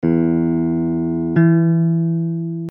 In the diagrams below, we are jumping from a note on an open string (any string will do) to another note on the same string.
Octave = 6 steps
octave.mp3